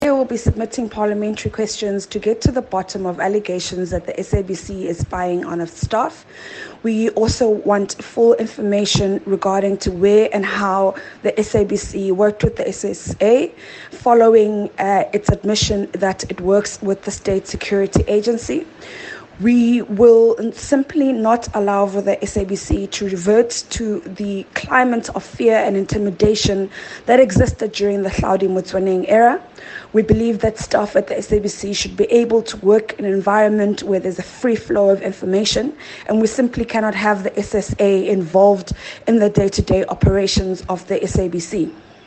soundbite by DA Shadow Minister of Communications, Telecommunications and Postal Services, Phumzile Van Damme